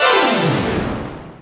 Synth Lick 49-07.wav